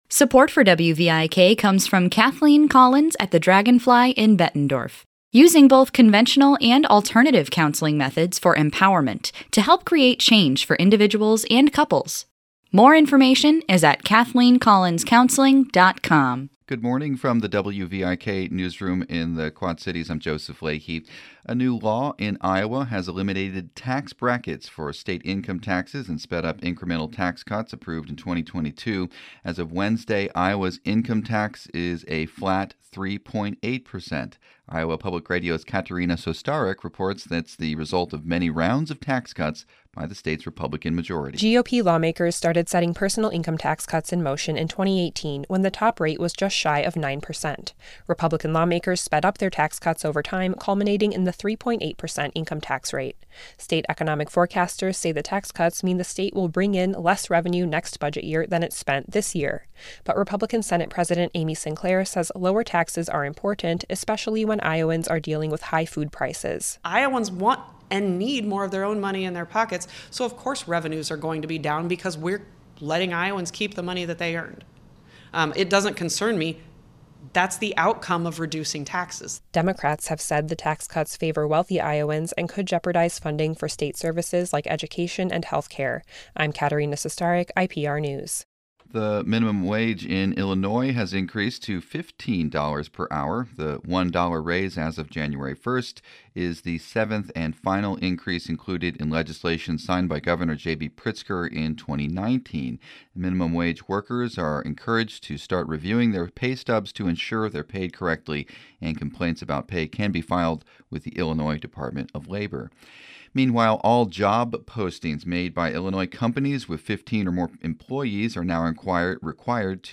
Morning headlines from WVIK News.